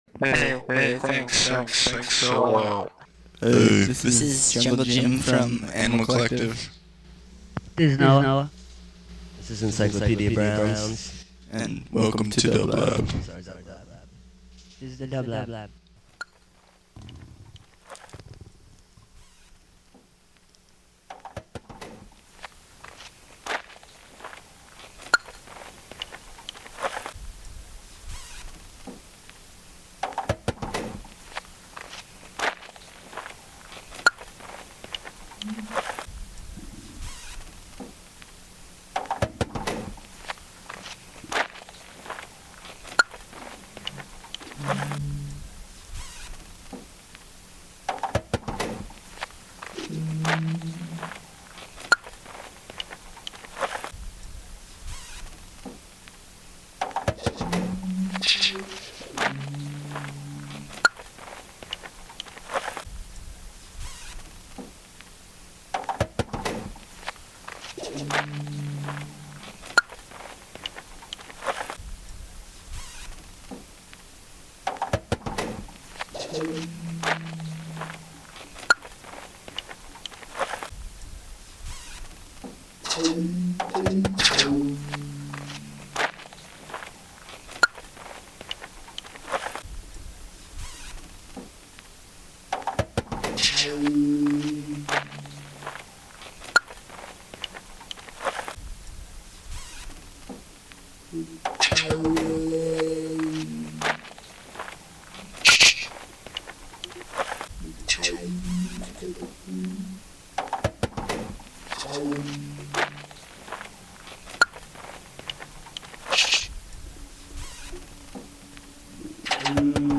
Avant-Garde Experimental